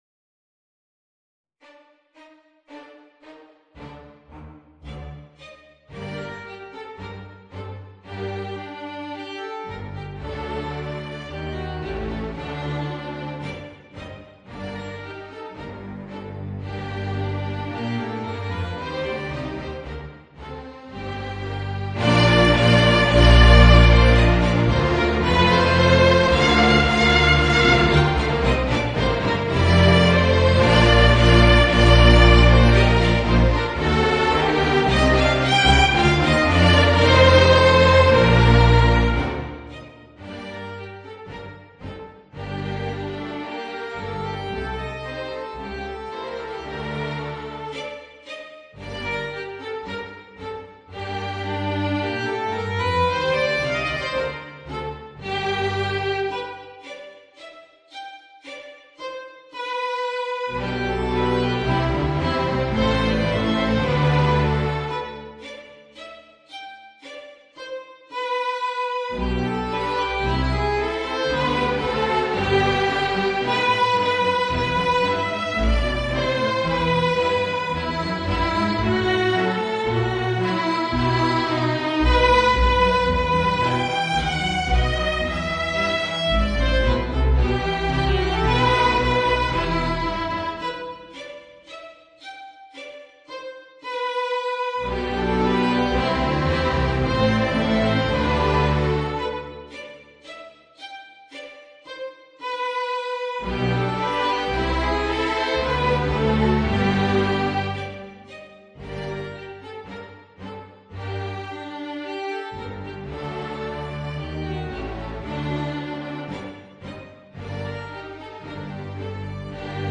Voicing: Violin and String Orchestra